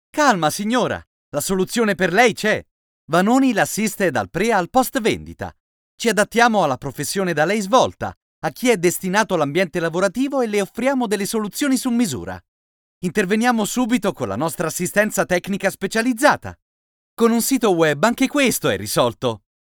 Ho una voce calda, profonda, versatile e dinamica!
Sprechprobe: Sonstiges (Muttersprache):
I have a warm, deep, versatile and dynamic voice!
Naturale, amichevole, sincero.mp3